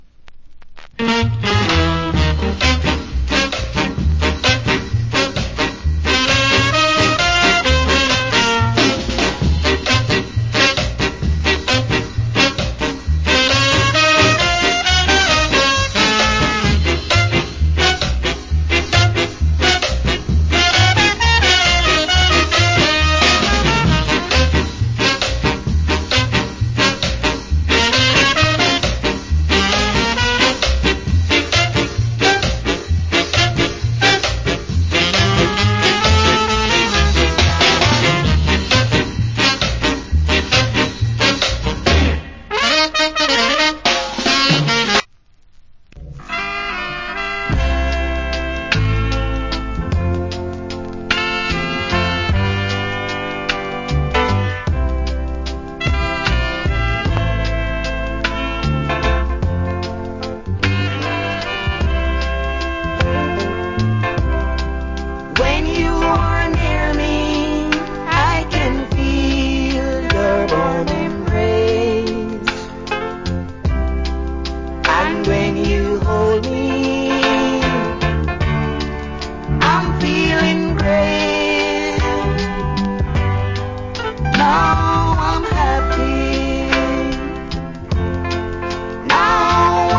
Rare. Killer Ska Inst.